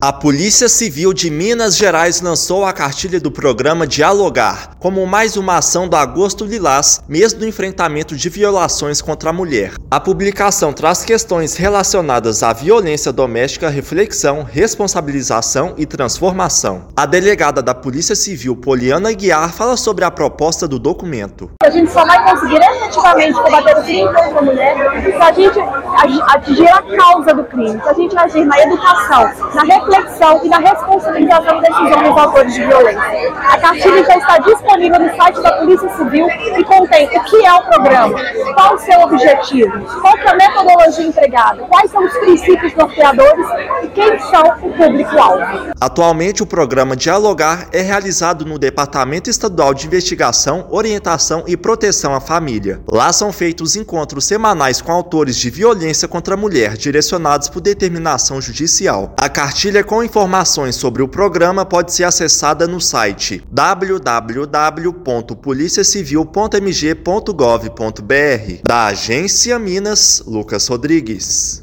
Material informativo divulga atuação da PCMG com autores de violência no enfrentamento e na prevenção de crimes contra a mulher. Ouça matéria de rádio.